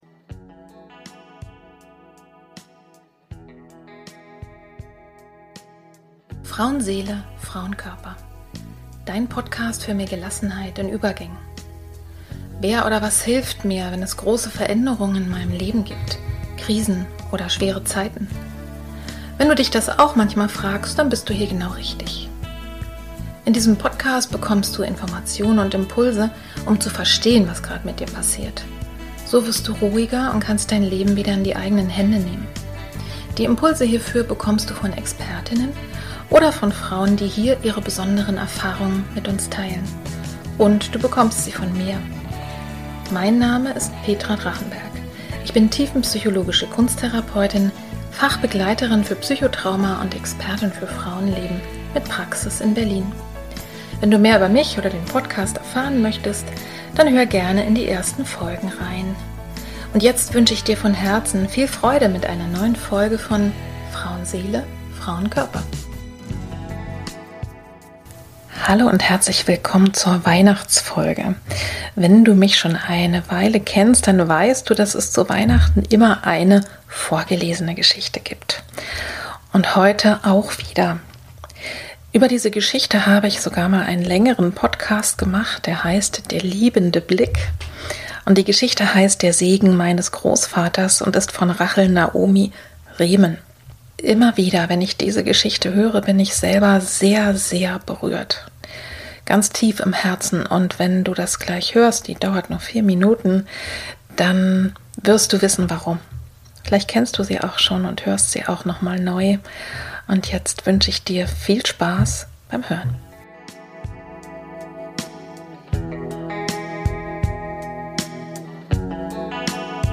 Wie schon seit vielen Jahren lese ich dir eine Geschichte vor, die dir die Weihnachtstage versüßen kann und dich vielleicht zum Nachdenken bringt.